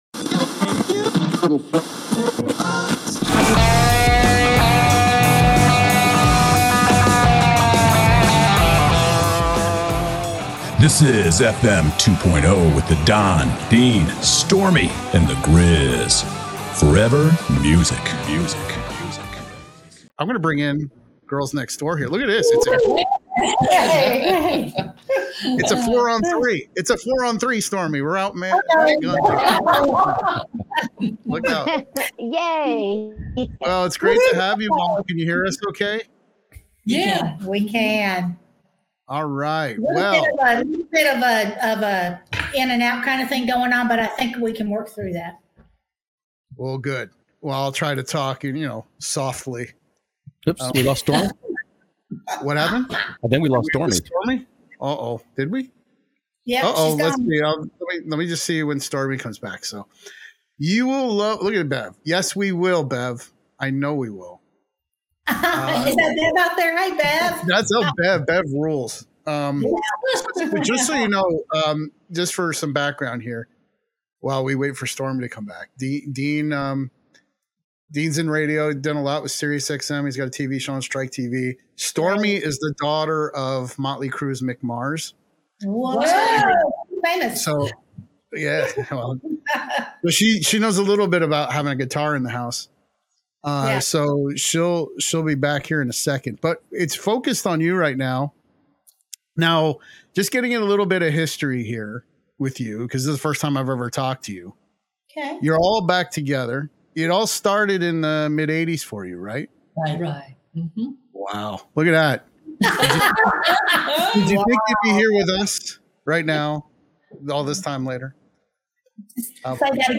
For example, after playing a clip of their song they picked it up LIVE and sang acapella and it sounded like we were all in heaven.